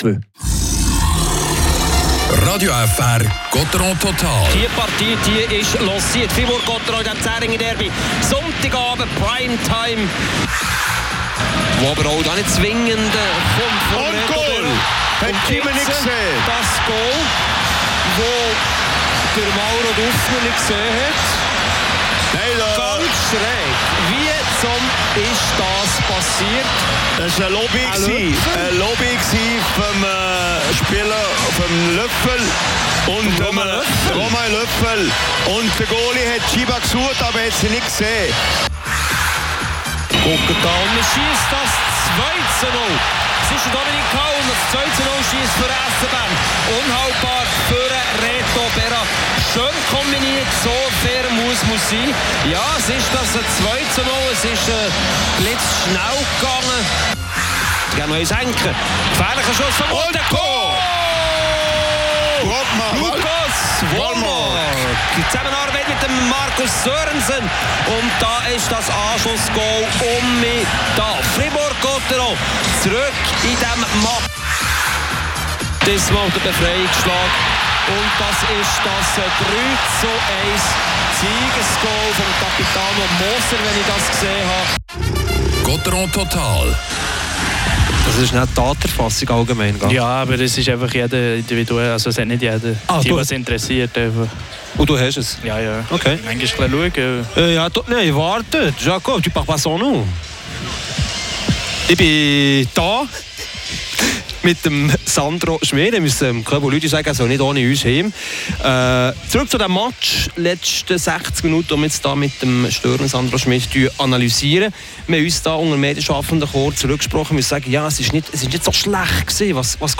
Highlights des Spiels, die Interviews